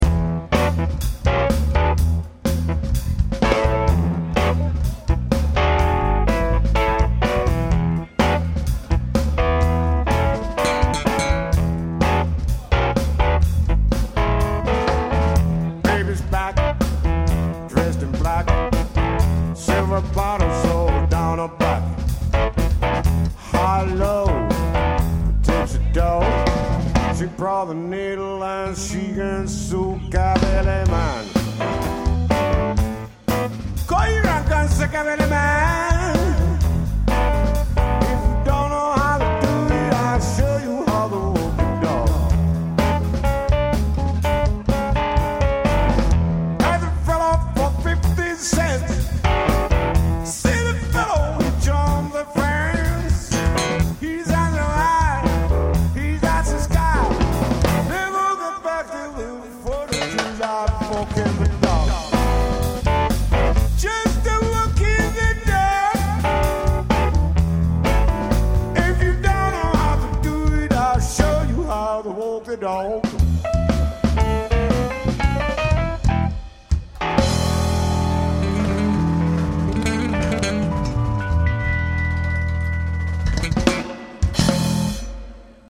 kosketinsoitin & laulu
kitara tai basso & laulu
rummut